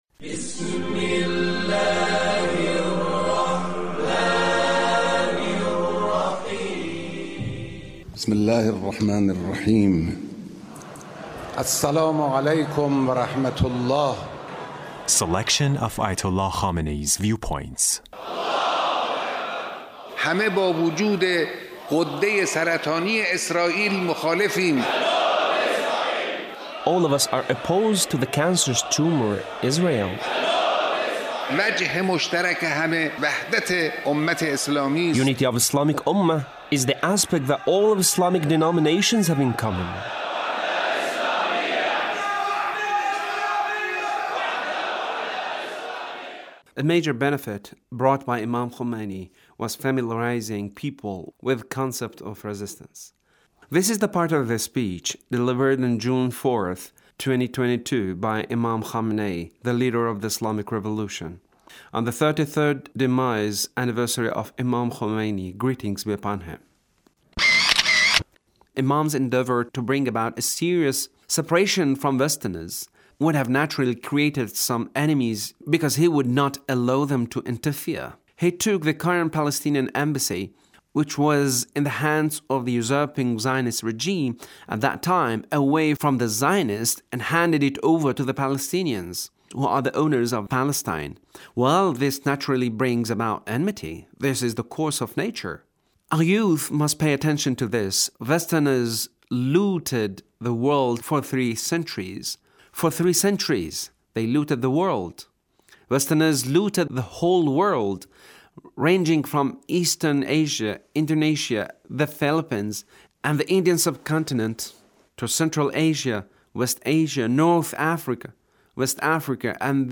Leader's speech (1445)